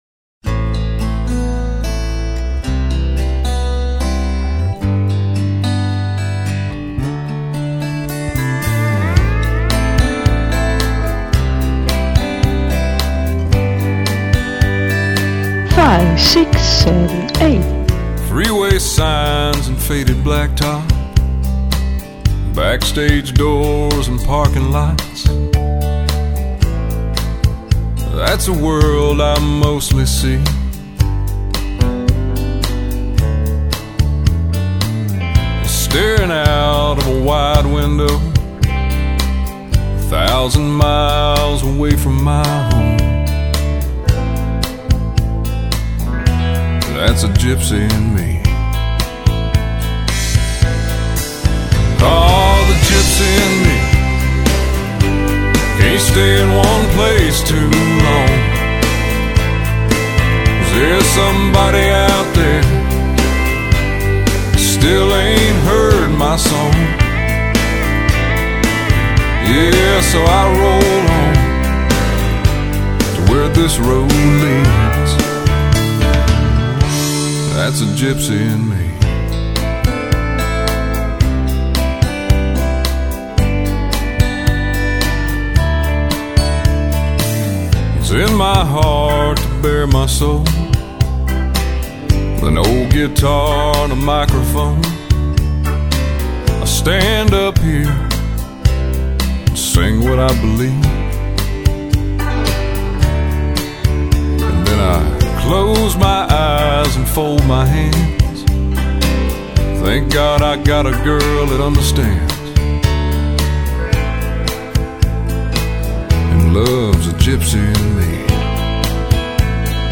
2013_Intermediate_Cuban_-_GYPSY_count_in.mp3